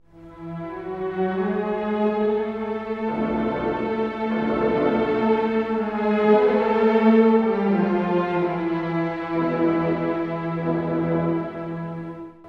↑ファンファーレの後は、「金管の3連符＋弦のエレジー」が続きます。
リズミックでありながら、重厚さが付加されています。